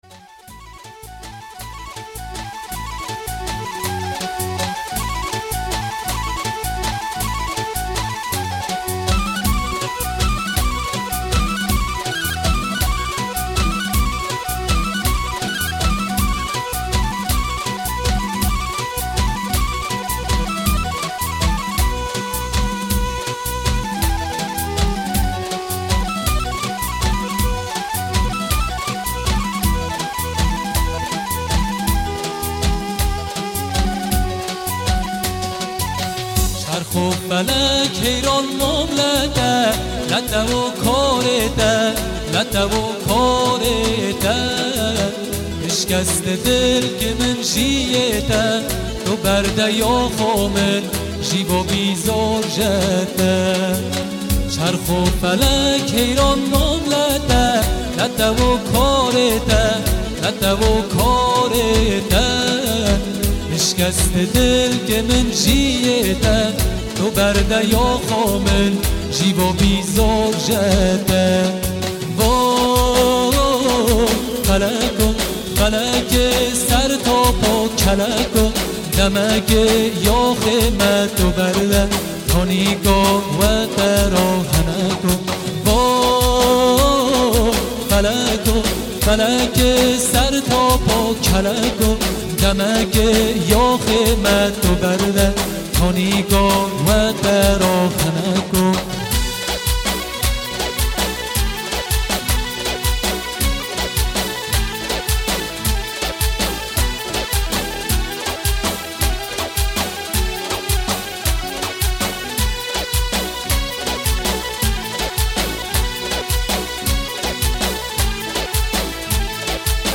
کرمانجی